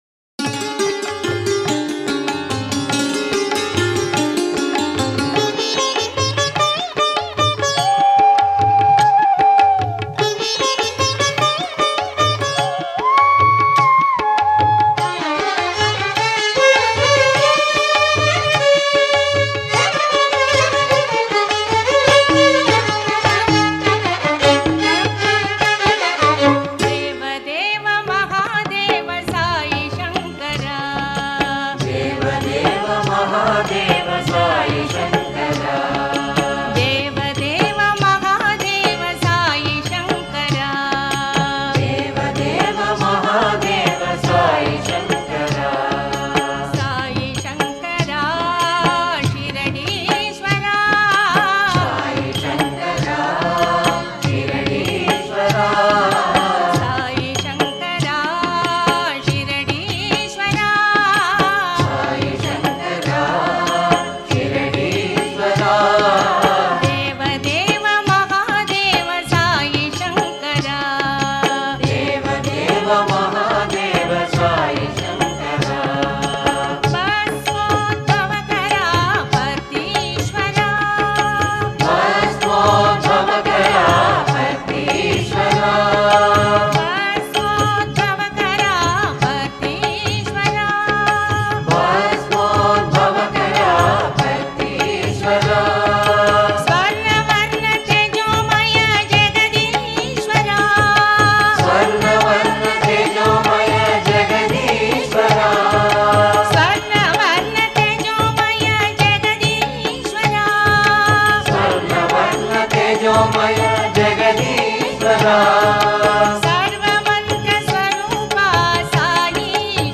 Author adminPosted on Categories Shiva Bhajans